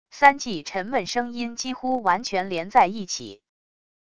三记沉闷声音几乎完全连在一起wav音频